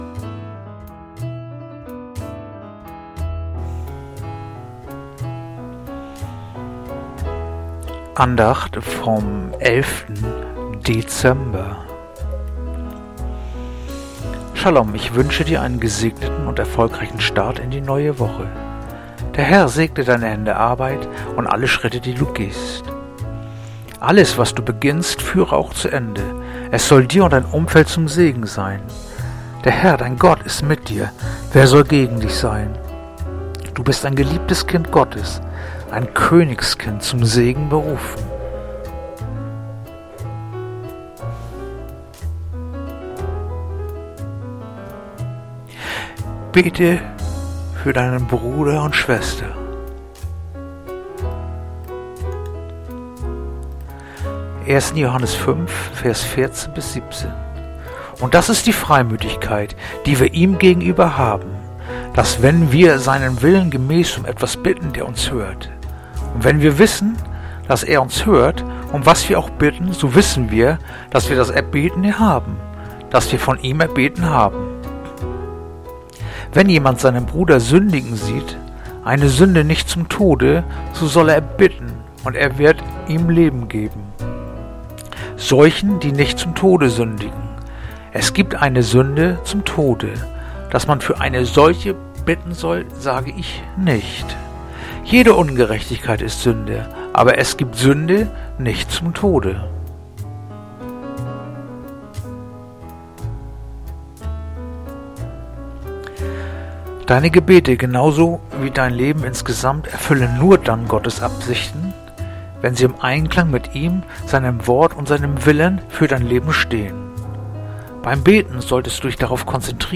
heutige akustische Andacht